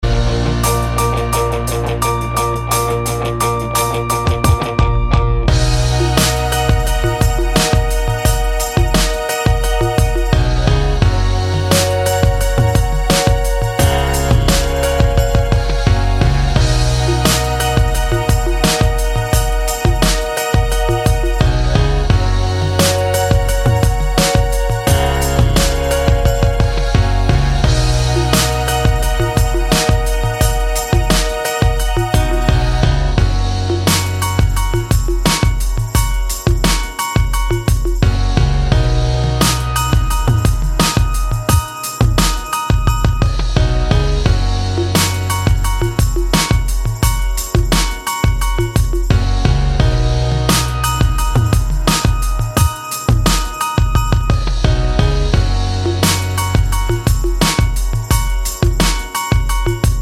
No Vocals At All R'n'B / Hip Hop 4:37 Buy £1.50